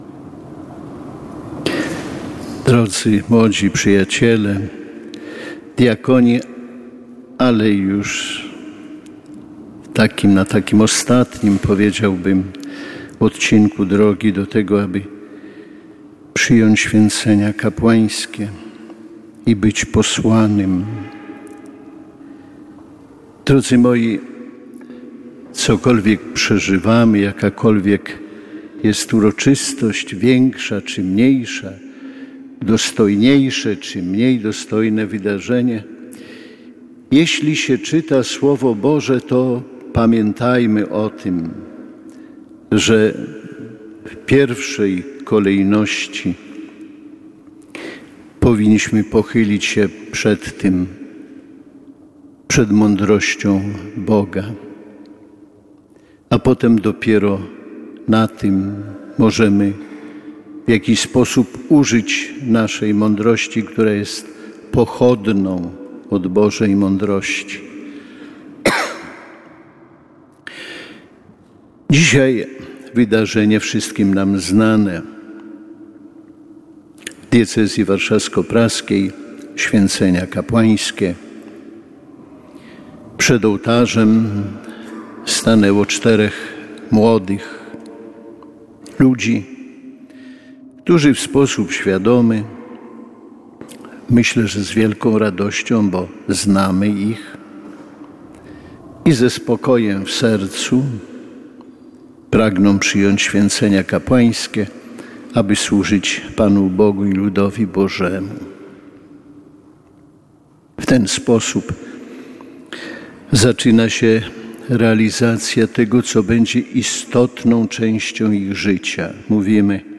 Uroczystości przewodniczył biskup diecezjalny Romuald Kamiński.
W homilii biskup przypomniał, że powołanie kapłańskie nie jest dziełem przypadku, lecz wpisane zostało w plan Boży wobec konkretnego człowieka:
romuald-homilia.mp3